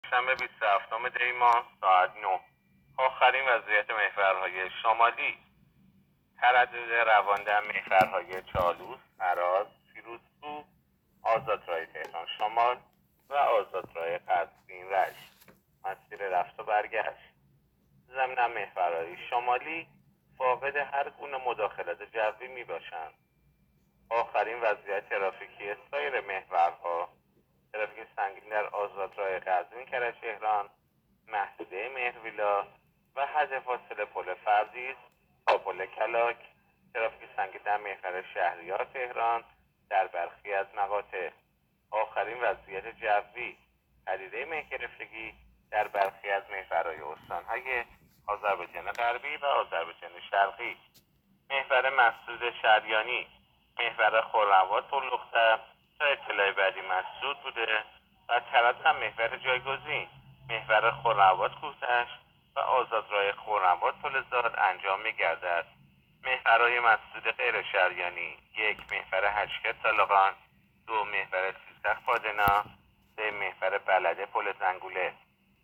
گزارش رادیو اینترنتی از آخرین وضعیت ترافیکی جاده‌ها تا ساعت ۹ بیست و هفتم دی؛